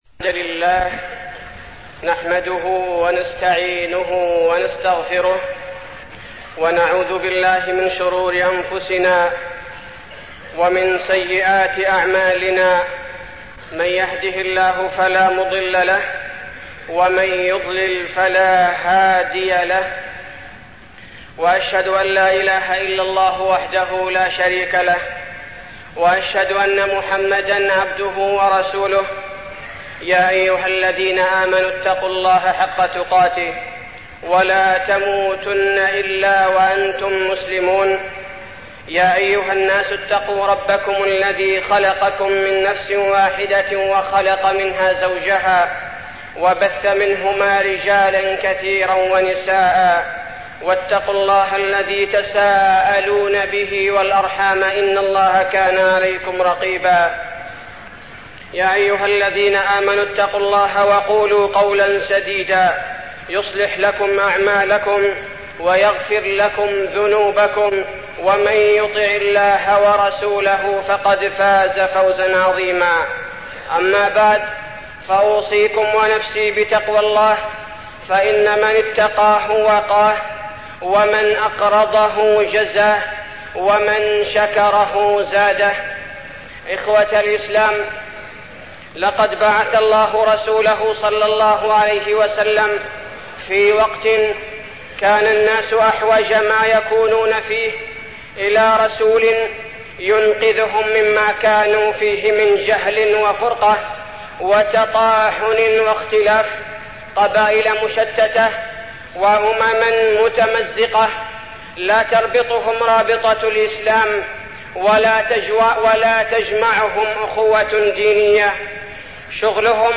تاريخ النشر ١٩ ذو القعدة ١٤١٧ هـ المكان: المسجد النبوي الشيخ: فضيلة الشيخ عبدالباري الثبيتي فضيلة الشيخ عبدالباري الثبيتي الإسلام والحياة